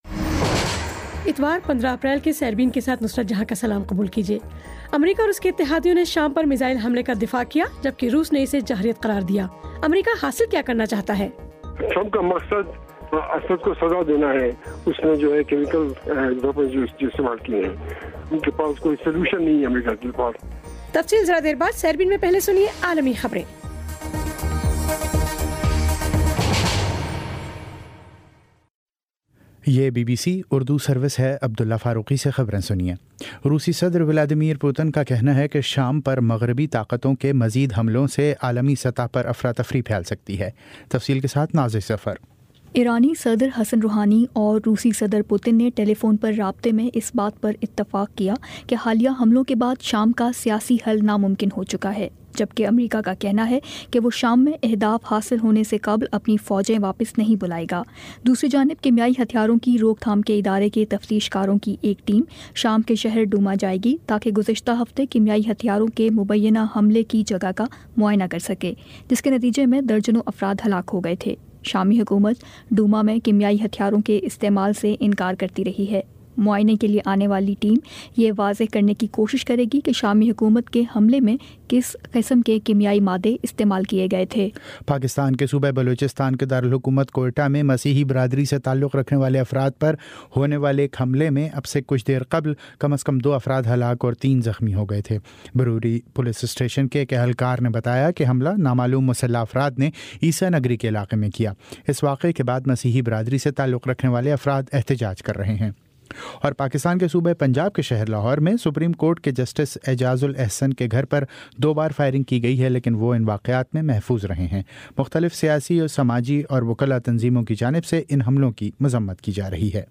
اتوار 15 اپریل کا سیربین ریڈیو پروگرام